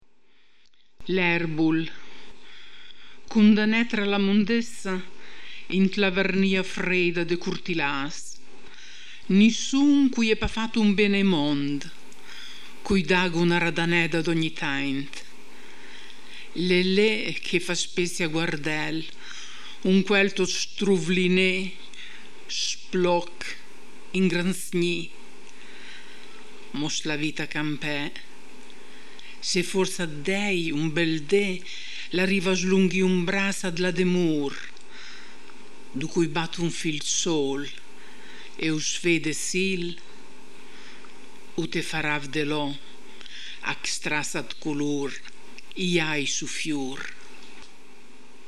voce recitante